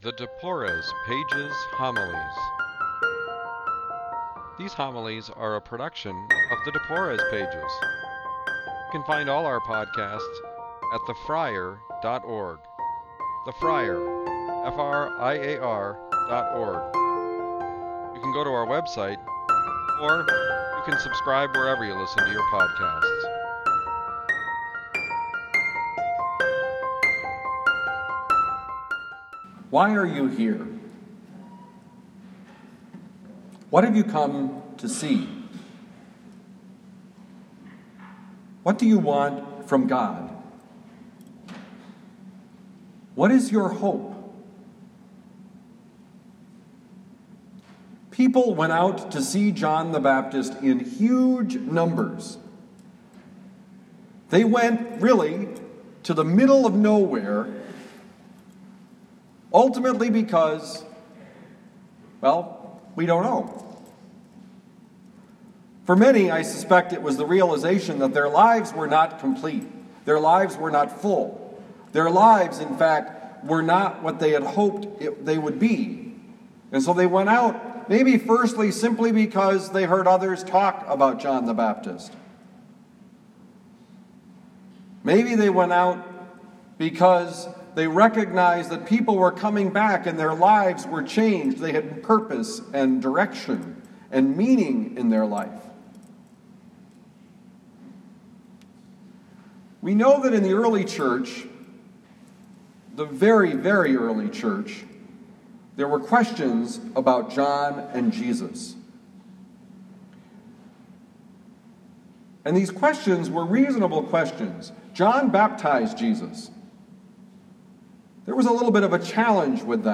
Homily given at Our Lady of Lourdes, University City, Missouri